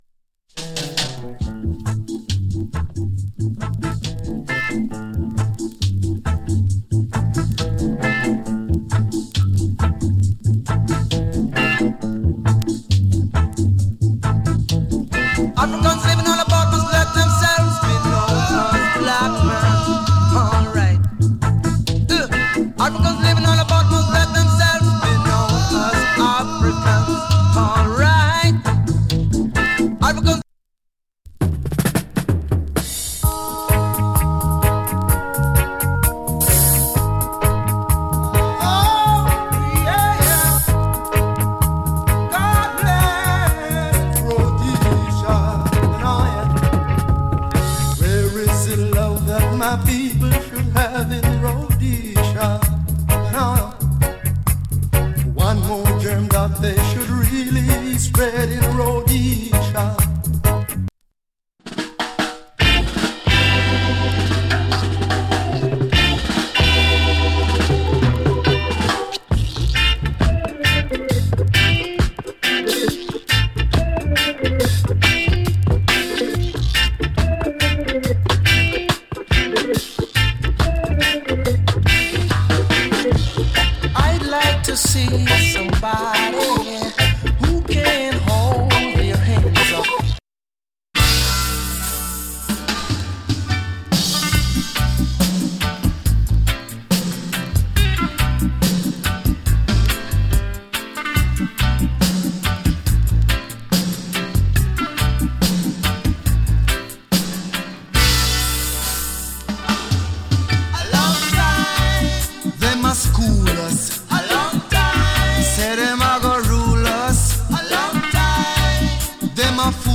KILLER TUNE から甘い VOCAL の REGGAE や ROCK STEADY まで有り !!